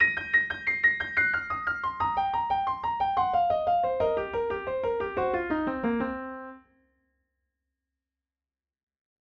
Piano Run over 3 Octaves
piano-blues-run-extra-octave.mp3